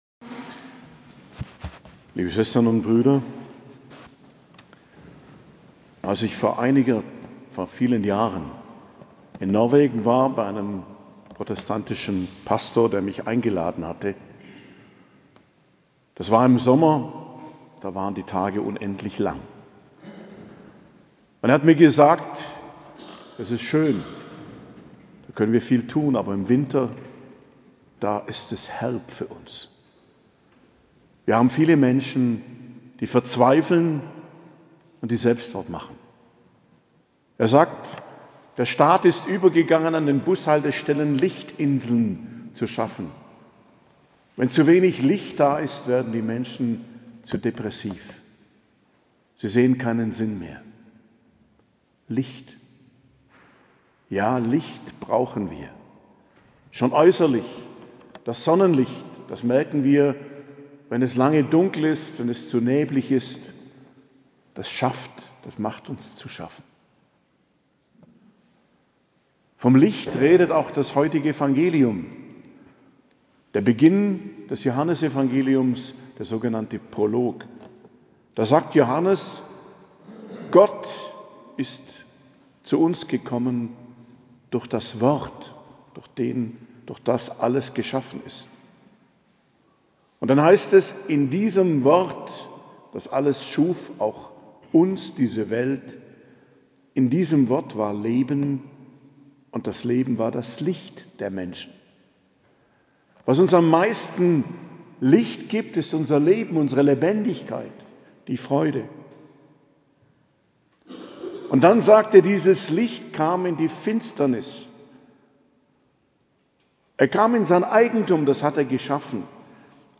Predigt zu Weihnachten - Hochfest der Geburt des Herrn, 25.12.2023